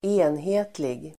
Uttal: [²'e:nhe:tlig]